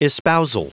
Prononciation du mot espousal en anglais (fichier audio)
Prononciation du mot : espousal